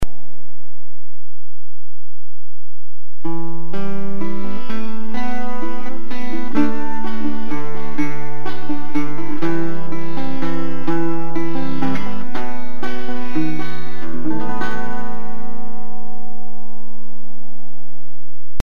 CITTERN
Cittern Sound Clips